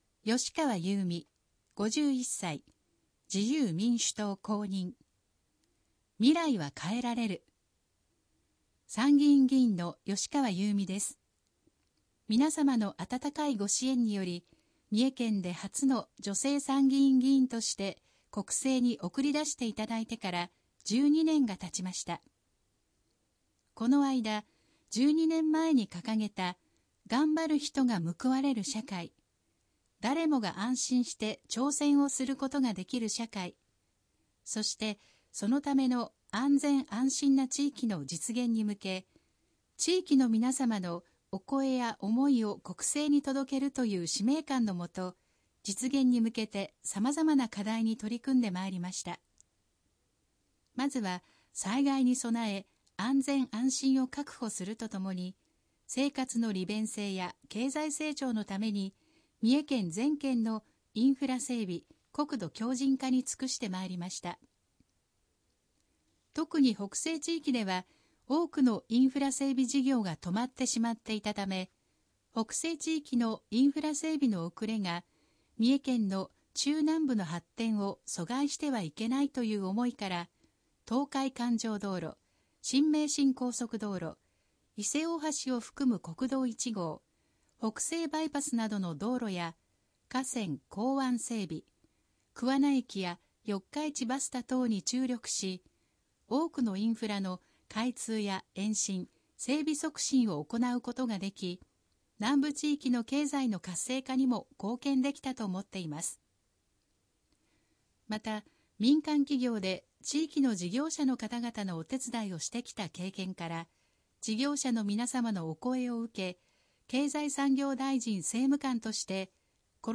（選挙公報の音声読み上げ対応データは、現時点で提出のあった候補者のみ掲載しています）